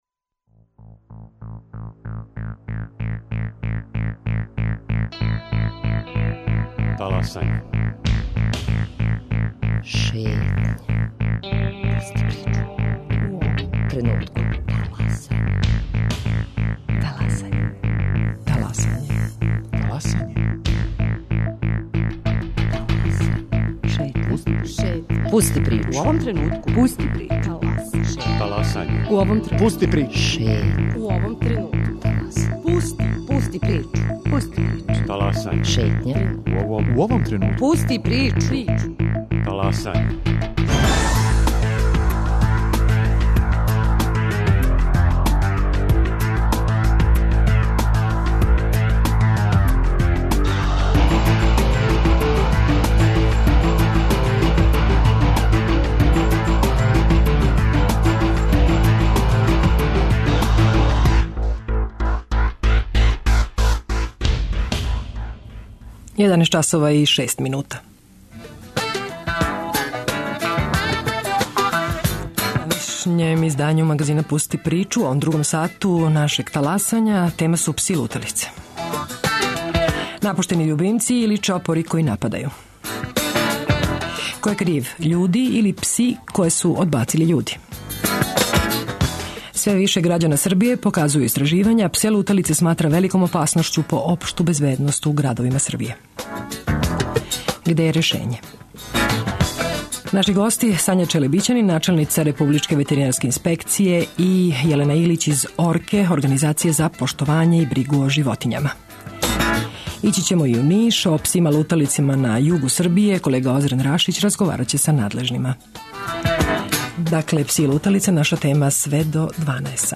Гости: Сања Челебичанин начелница Републичке ветеринарске инспекције